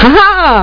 HAHA!.mp3